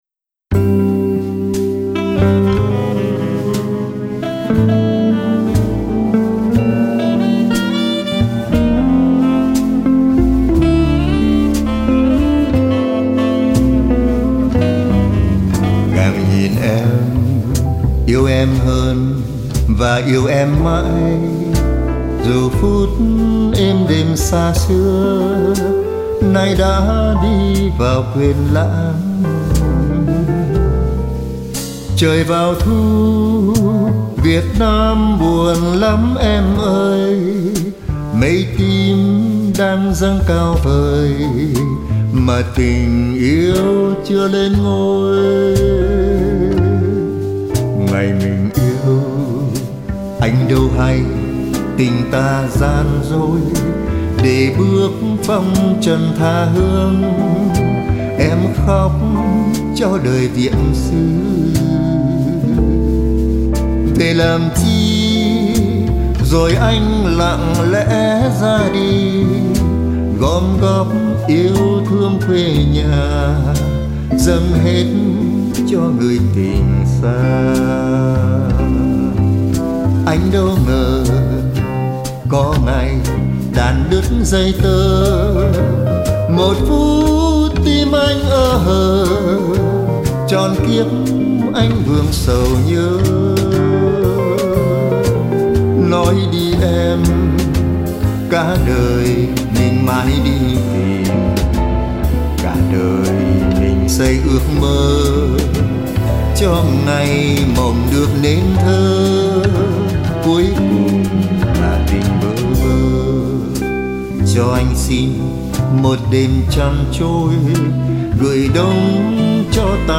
jazz ballad ngọt ngào, đằm thắm